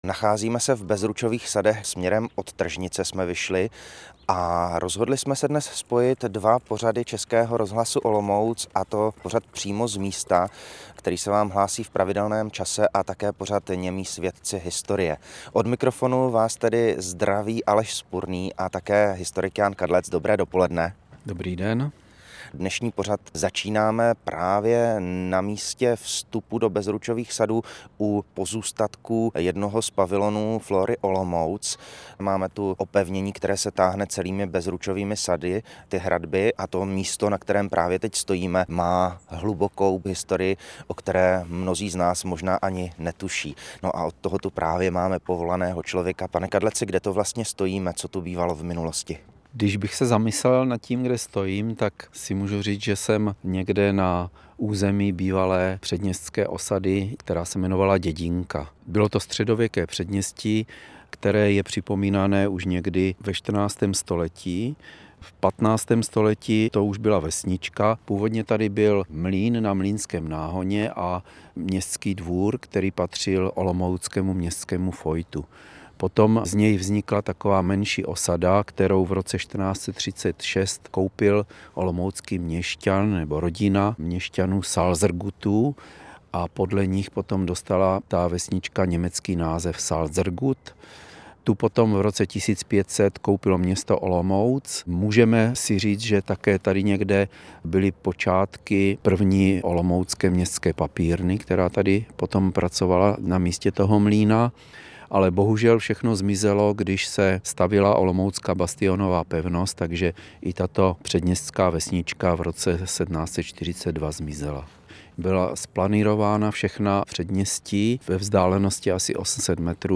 Větrník - Host ve studiu: Policejní pes Umpalumpa zvládá mnoho disciplín.